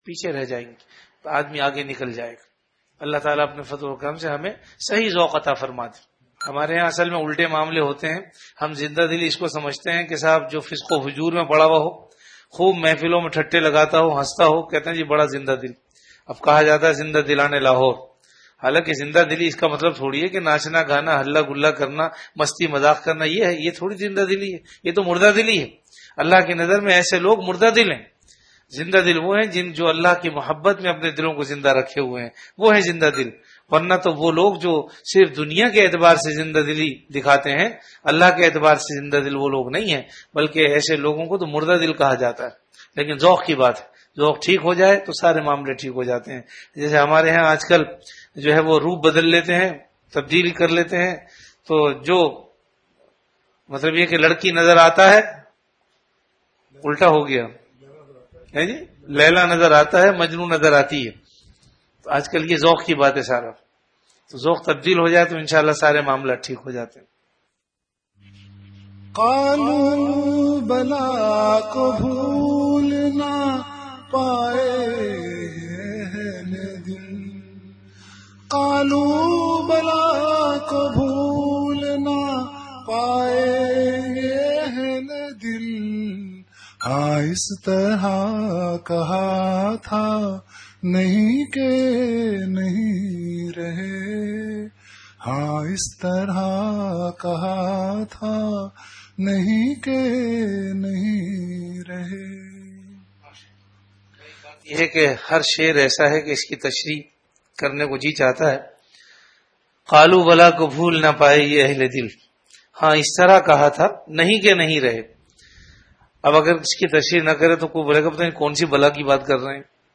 Delivered at Home.
Category Majlis-e-Zikr
Event / Time After Isha Prayer